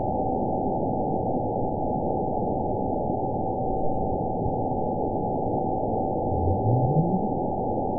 event 912625 date 03/30/22 time 13:59:26 GMT (3 years, 1 month ago) score 9.70 location TSS-AB01 detected by nrw target species NRW annotations +NRW Spectrogram: Frequency (kHz) vs. Time (s) audio not available .wav